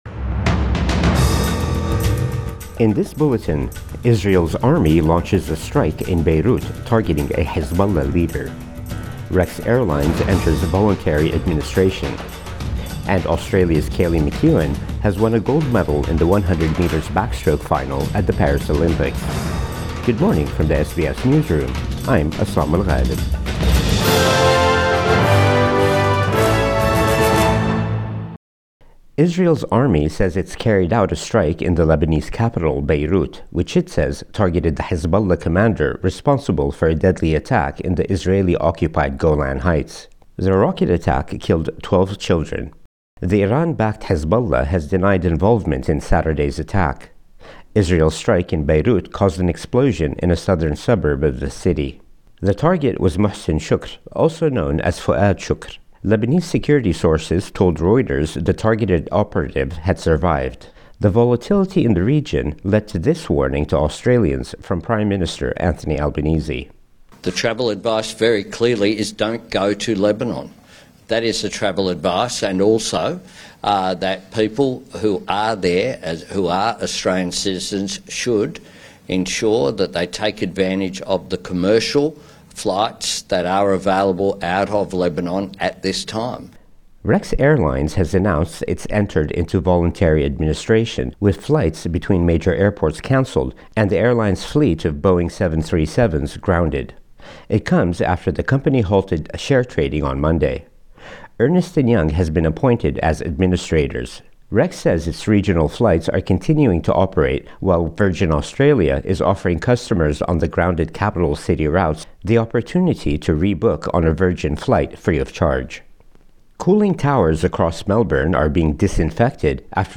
Morning News Bulletin 31 July 2024